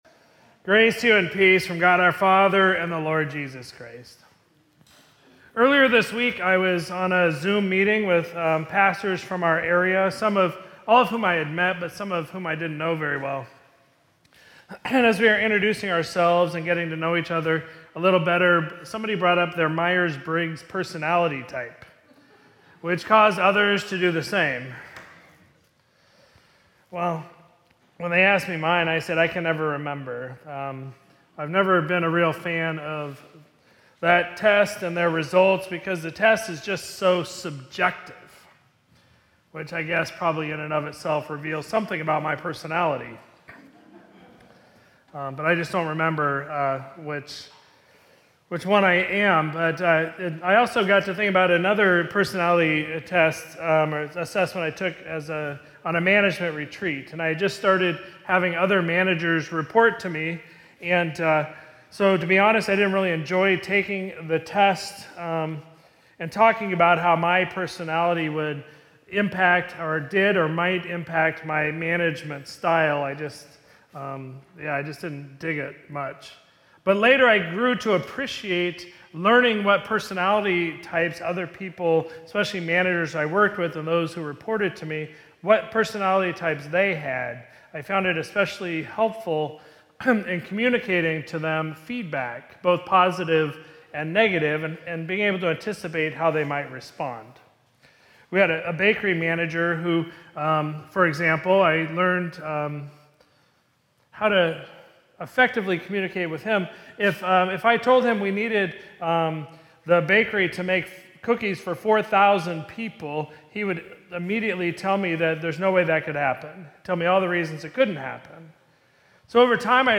Sermon from Sunday, March 1, 2026